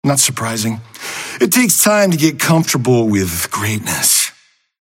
Lash voice line - Not surprising.